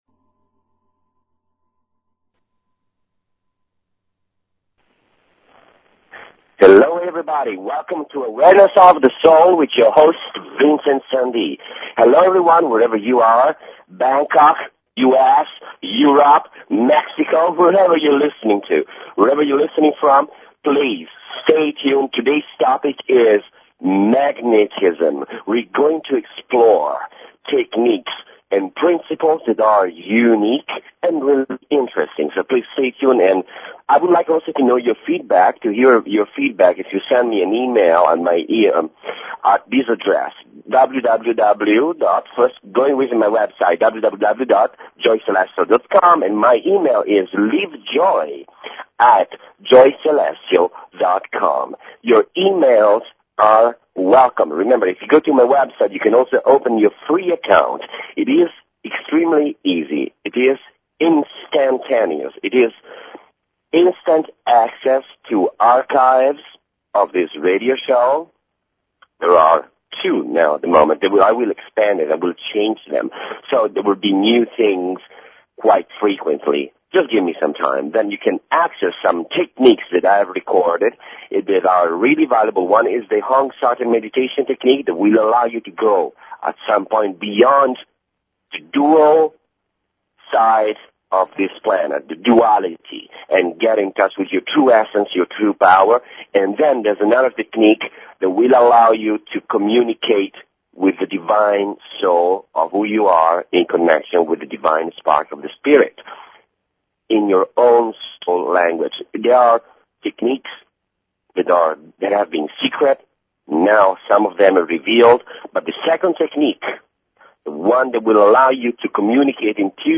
Talk Show Episode, Audio Podcast, Awareness_of_the_Soul and Courtesy of BBS Radio on , show guests , about , categorized as